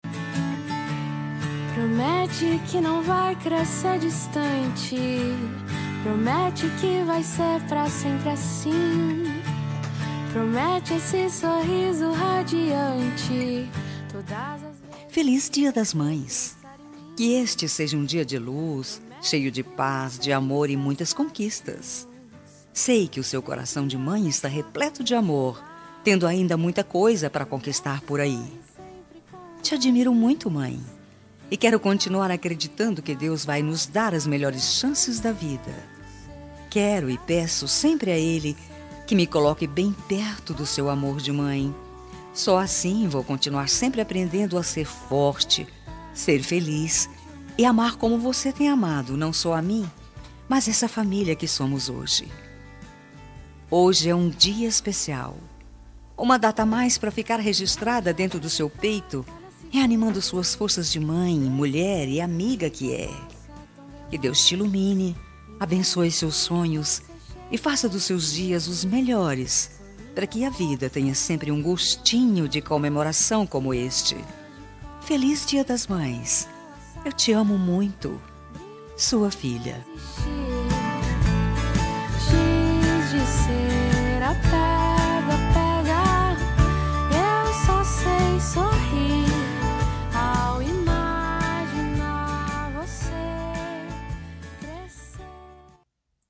Dia das Mães – Para minha Mãe – Voz Feminina – Cód: 6505